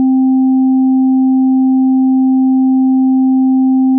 Band Limited Triangle Wave
trianglebl.wav